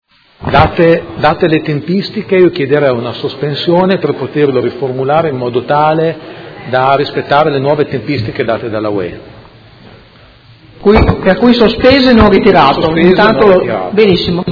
Giuseppe Pellacani — Sito Audio Consiglio Comunale
Seduta del 9/11/2017. Sospende Ordine del Giorno presentato dai Consiglieri Pellacani e Galli (FI) avente per oggetto: Preoccupazione per gli effetti sull’economia italiana, dell’Emilia Romagna e di Modena in particolare per le conseguenze delle sanzioni UE nei confronti della Russia